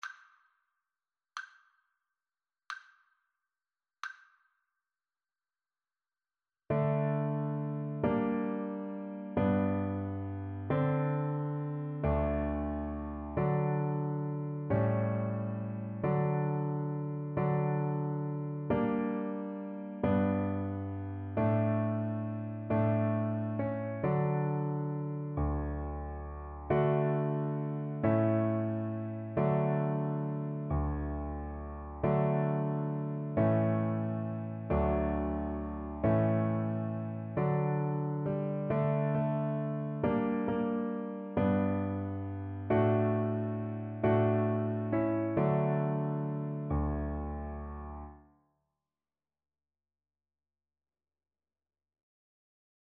D major (Sounding Pitch) (View more D major Music for Violin )
3/4 (View more 3/4 Music)
One in a bar .=45
World (View more World Violin Music)